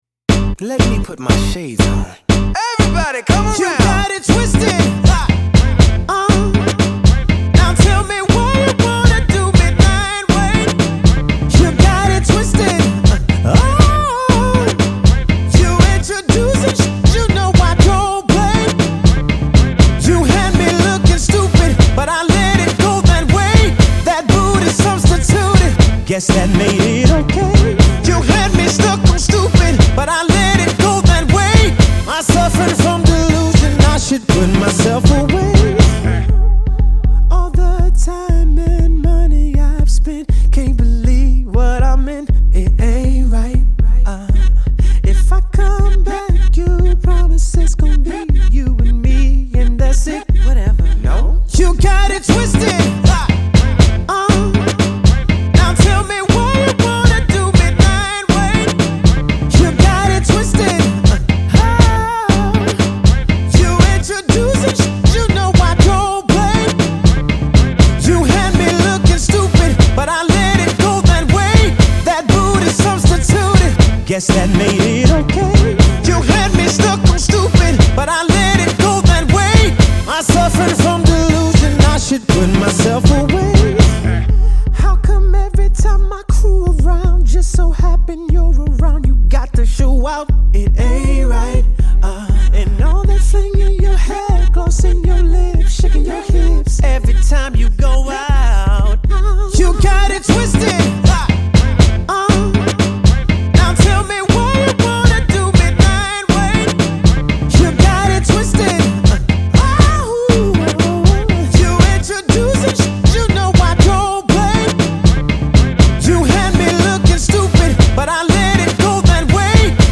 A summer jam if there ever was one.